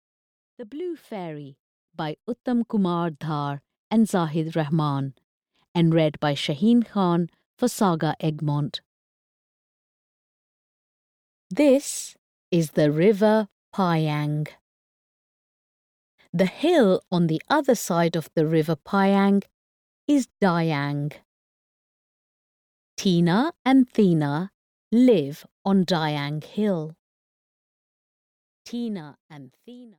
The Blue Fairy (EN) audiokniha
Ukázka z knihy